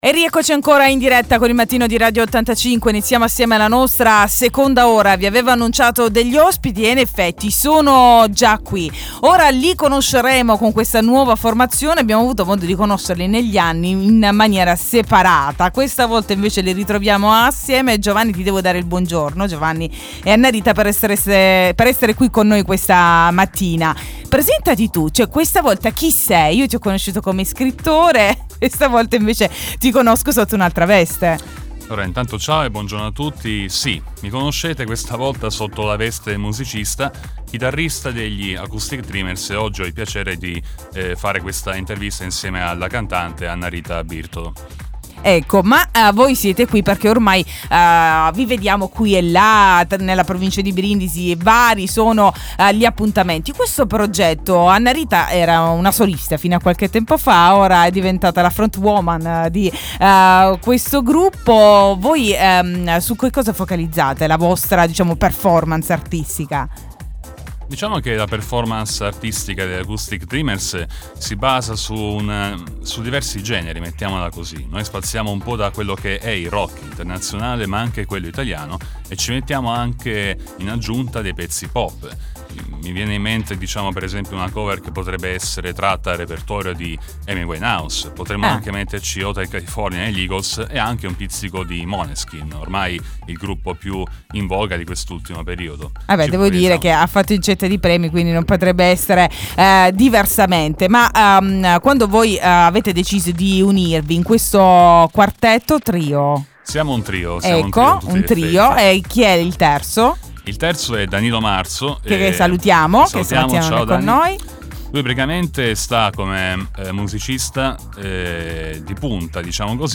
The Acoustic Dreamers in diretta oggi nel Mattino di Radio 85.